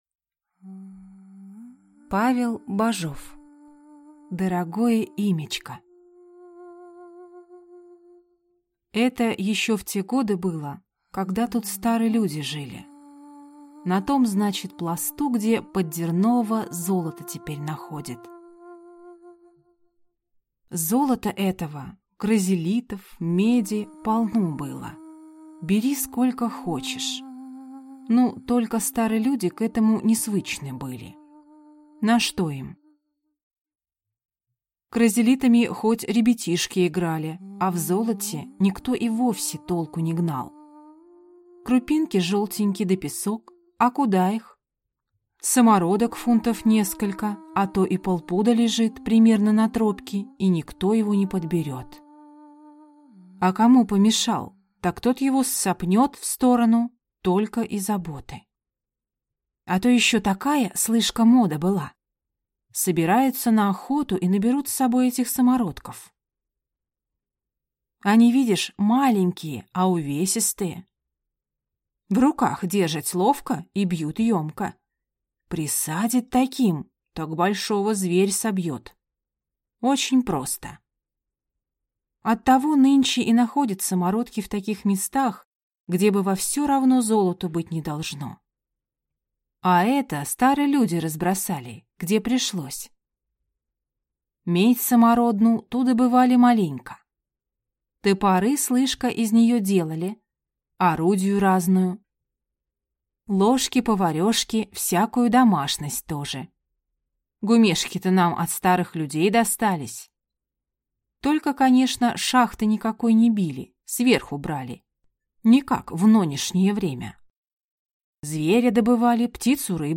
Аудиокнига Дорогое имячко | Библиотека аудиокниг
Читает аудиокнигу